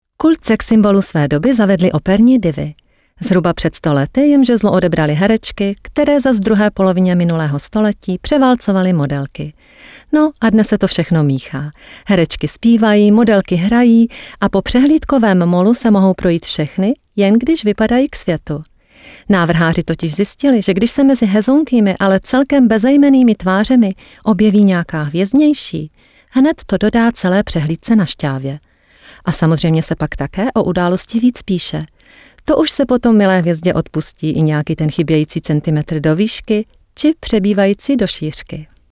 Native Sprecherin für Tschechisch
Sprechprobe: eLearning (Muttersprache):
native-speaker for Czech language